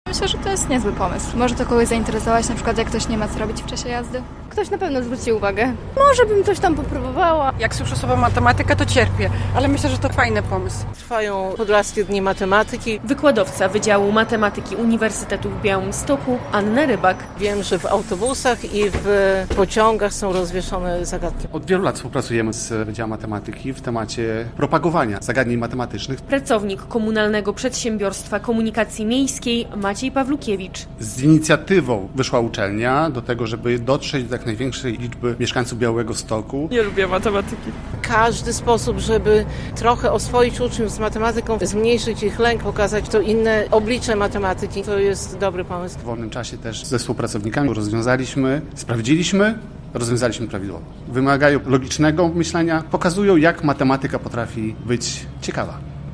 Zagadki matematyczne w autobusach komunikacji miejskiej - relacja